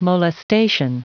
Prononciation du mot molestation en anglais (fichier audio)